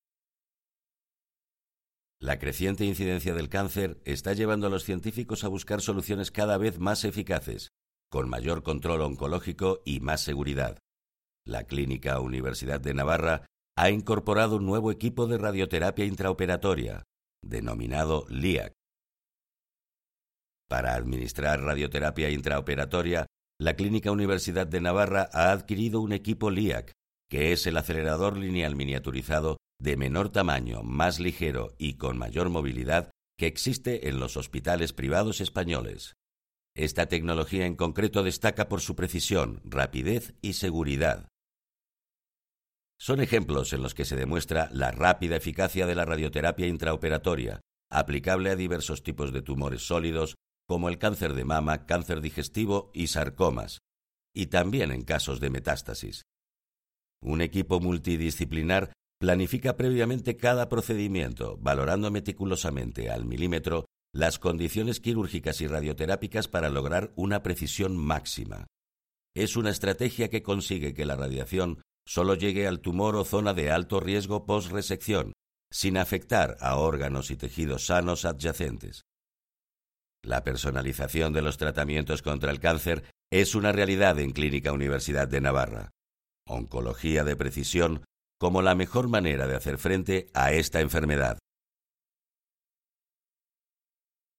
Cercanía, amable, afable, versátil, publicidad, doblaje, interpretación.
Kein Dialekt
Sprechprobe: eLearning (Muttersprache):